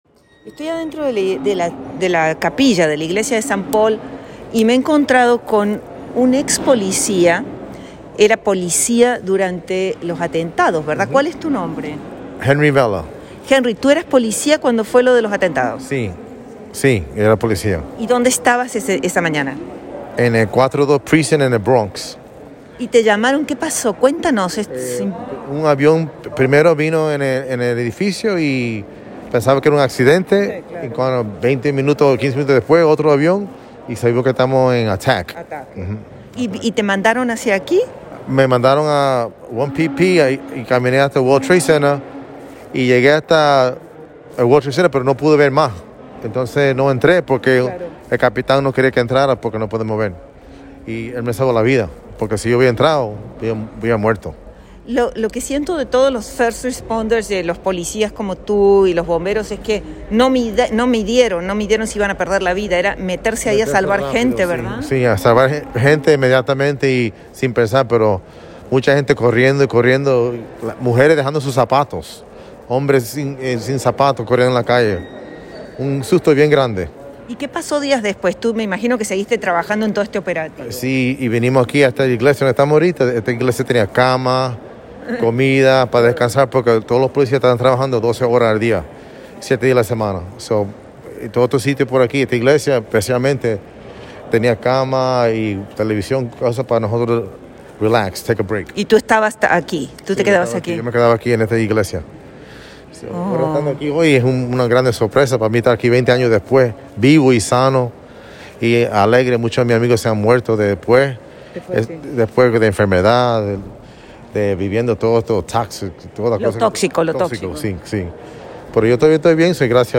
Audio. El relato de un ex policía rescatista del atentado del 11 S